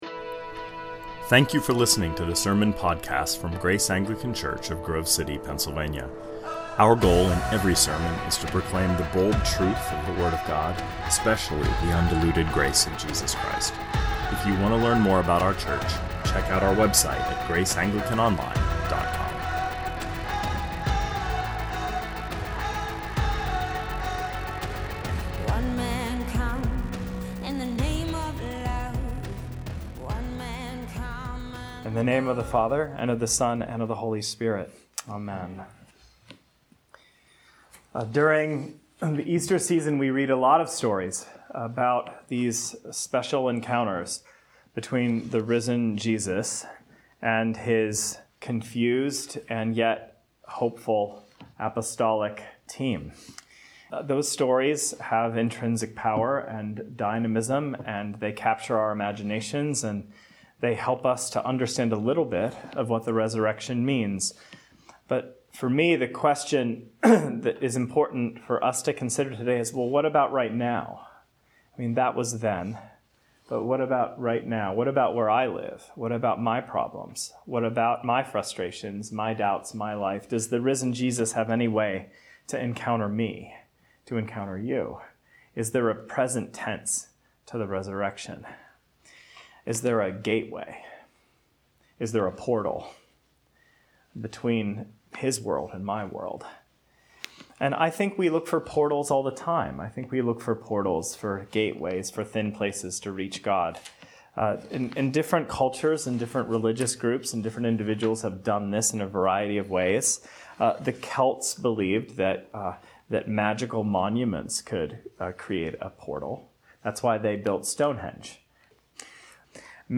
2020 Sermons